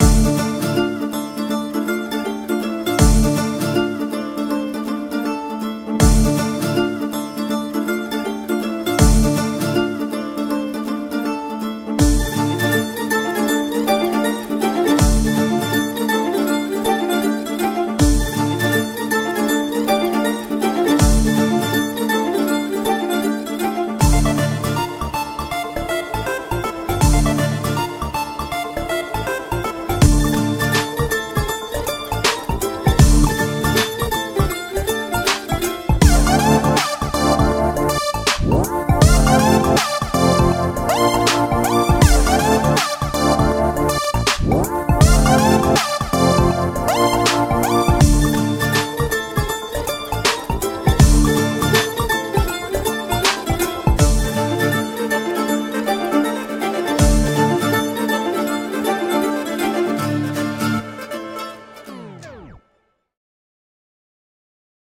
removed dialogues and disturbances with utmost perfection.